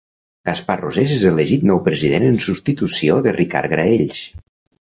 Женственный
[sups.ti.tu.siˈo]